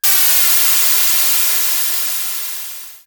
GasReleasing11.wav